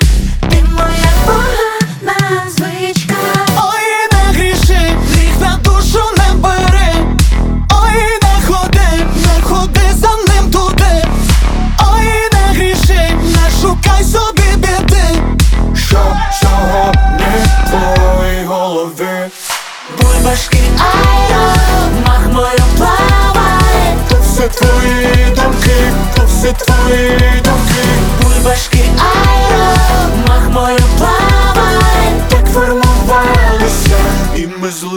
Жанр: R&B / Соул / Диско / Украинские